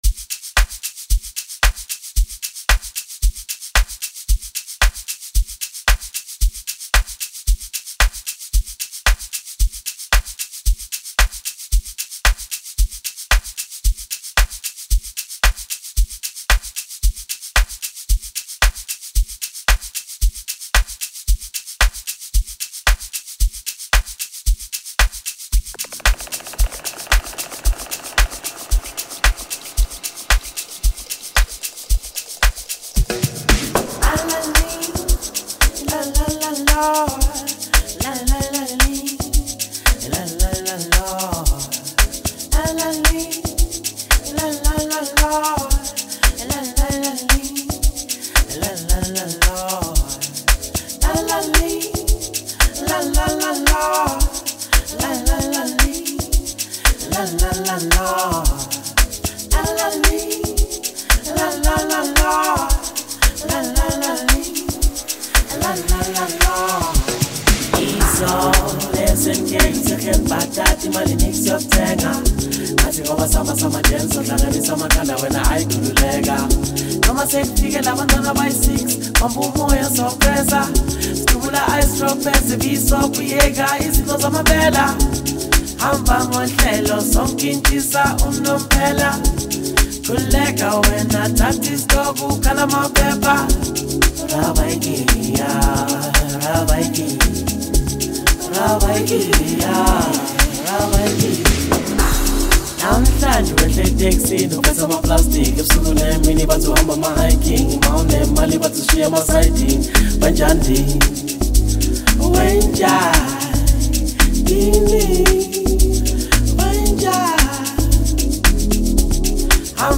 blissful Amapiano Tech production